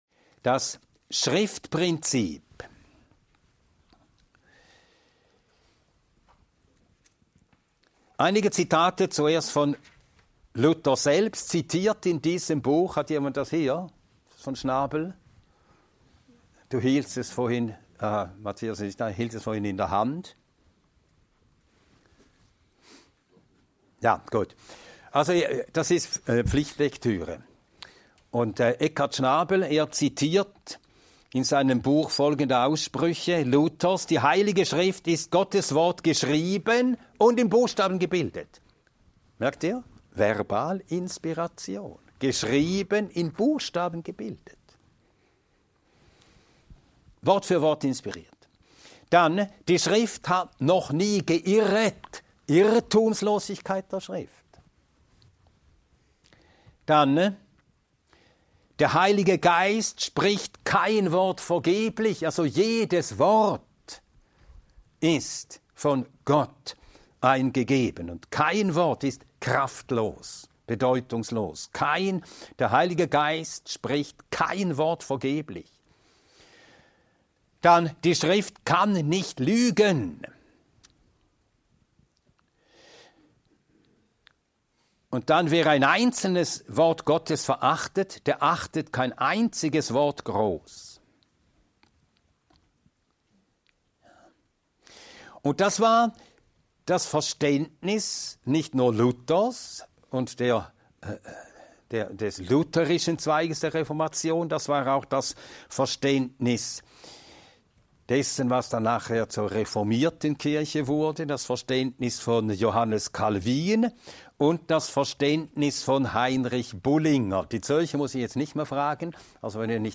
Ein mehrteiliger Vortrag